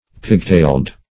Pigtailed \Pig"tailed`\, a. Having a tail like a pig's; as, the pigtailed baboon.